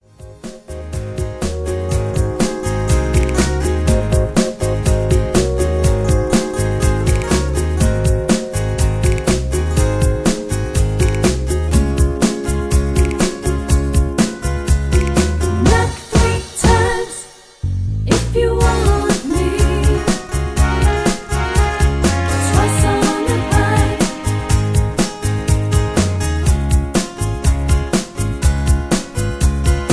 Tags: backing tracks , karaoke , sound tracks